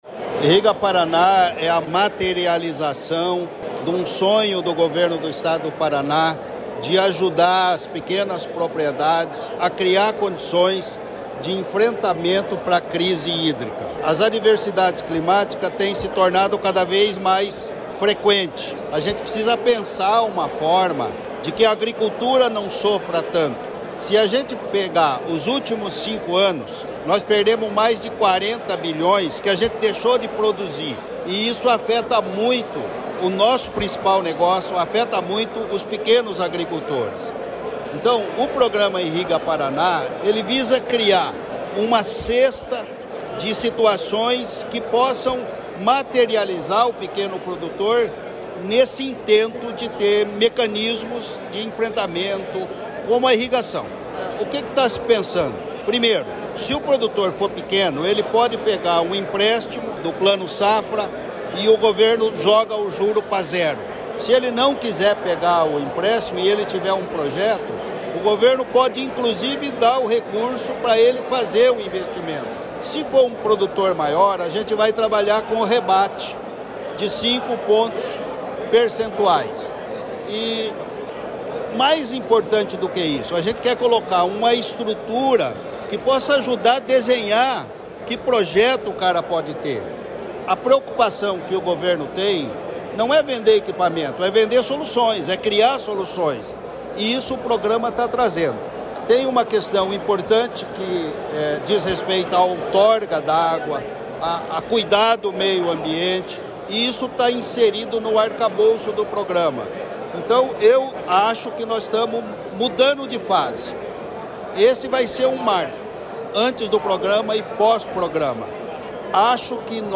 Sonora do secretário da Agricultura e do Abastecimento, Natalino Avance de Souza, sobre o lançamento do Programa Irriga Paraná